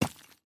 immersive-sounds / sound / footsteps / resources / ore-02.ogg